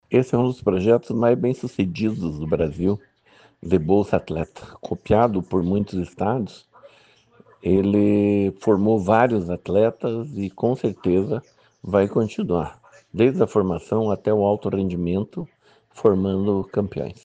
Sonora do secretário Estadual do Esporte, Hélio Wirbiski, sobre a instituição oficial do Programa Geração Olímpica e Paralímpica